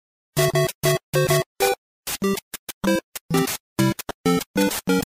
Videogame music and sound effects